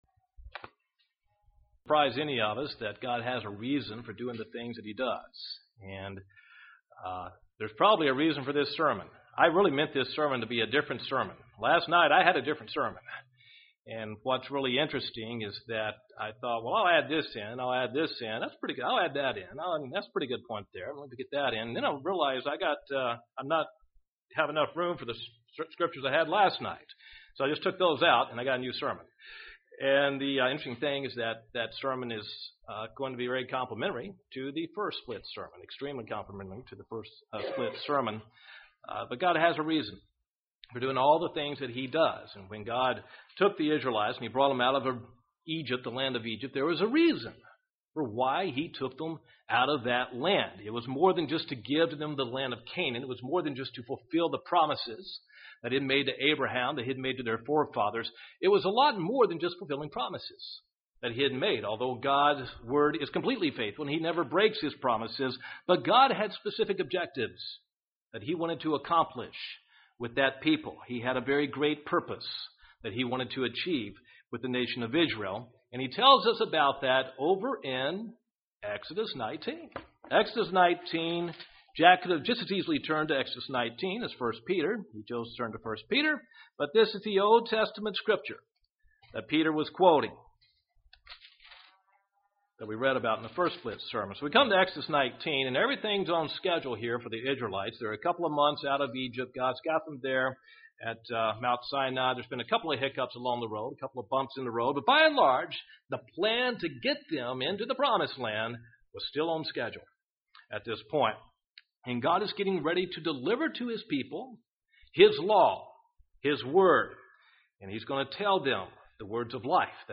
Given in Huntsville, AL